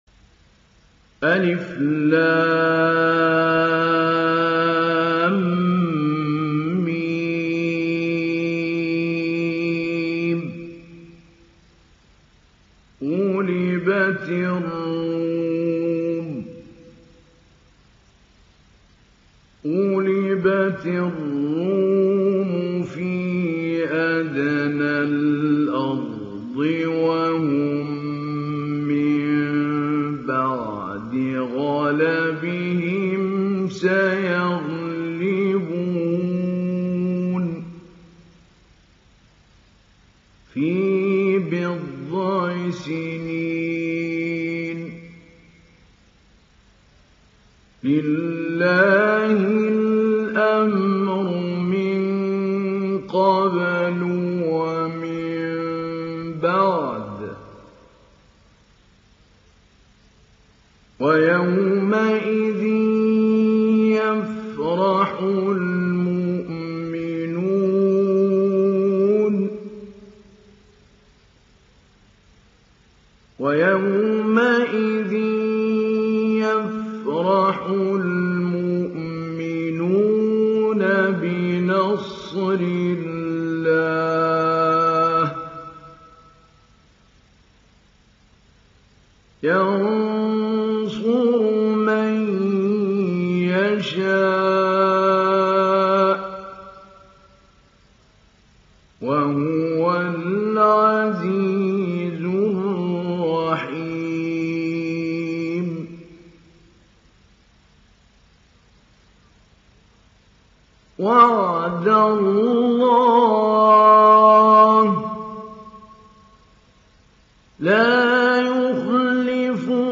Surah Ar Rum Download mp3 Mahmoud Ali Albanna Mujawwad Riwayat Hafs from Asim, Download Quran and listen mp3 full direct links
Download Surah Ar Rum Mahmoud Ali Albanna Mujawwad